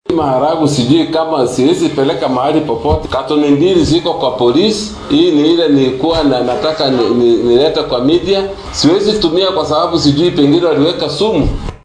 Mid ka mid ah dadka la khiyaanay ayaa sidatan dareenkiisa ahaa.
Mid-ka-mid-ah-dadka-dhaca-loo-gaystay.mp3